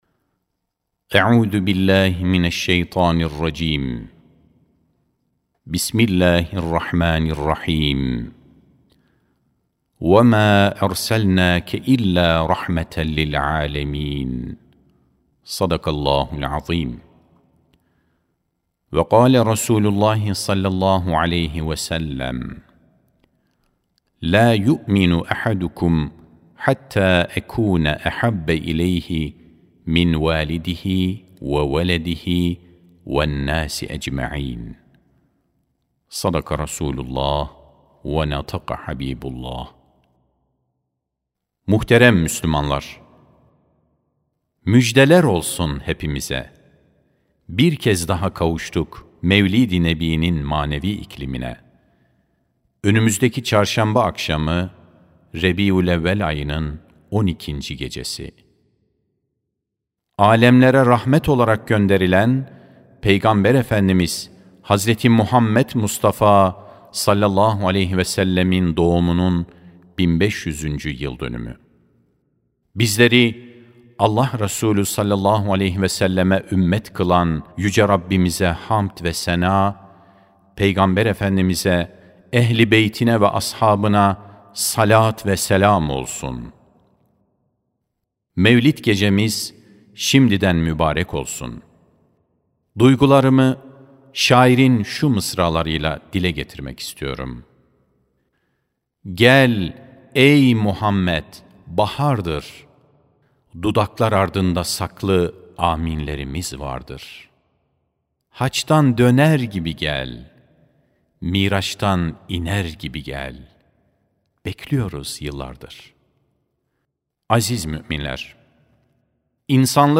29.08.2025 Cuma Hutbesi: Âlemlere Rahmet Hz. Muhammed (s.a.s) (Sesli Hutbe, Türkçe, İngilizce, İspanyolca, İtalyanca, Rusça)
Sesli Hutbe (Âlemlere Rahmet Hz. Muhammed (s.a.s)).mp3